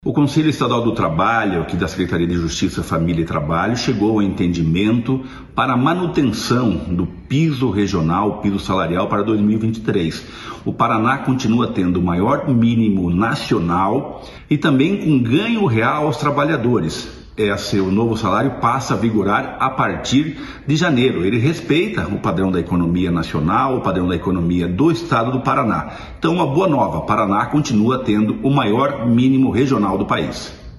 Sonora do secretário da Sejuf, Rogério Carboni, sobre o novo salário mínimo regional